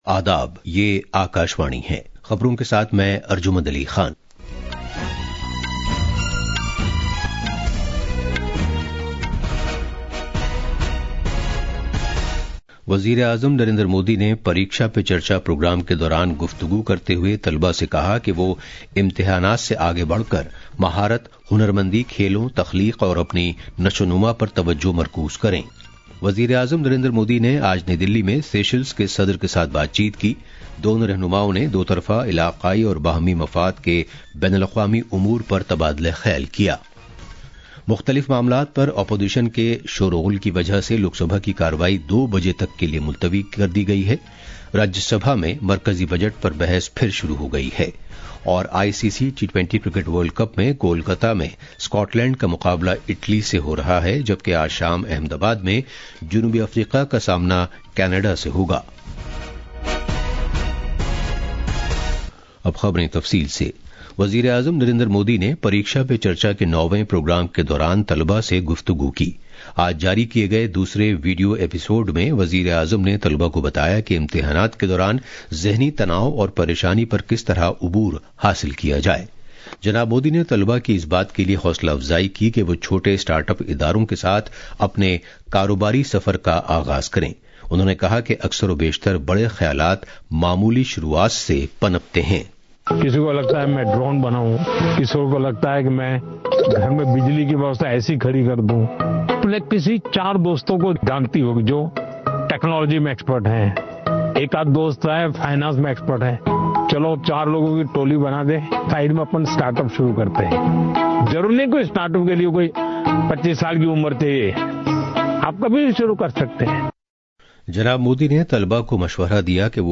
National Bulletins